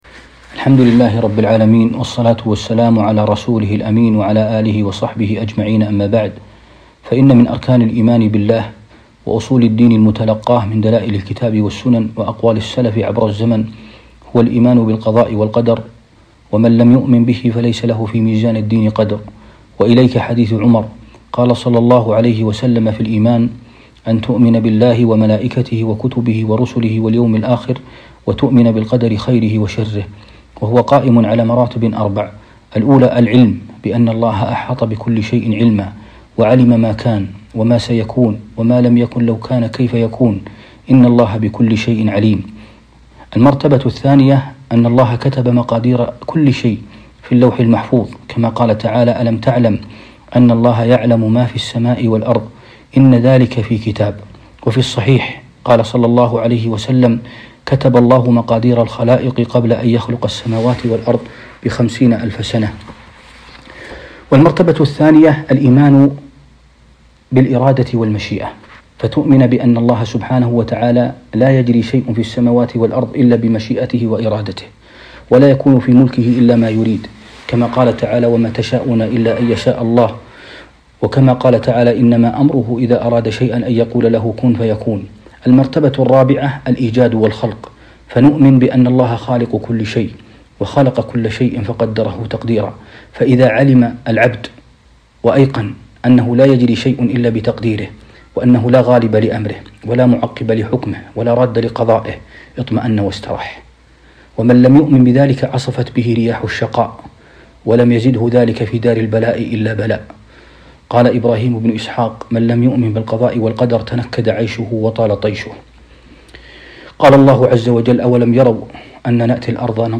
محاضرة - هدي النبي ﷺ مع أصحاب المصائب والإبتلاء عبر البث المباشر 1441هــ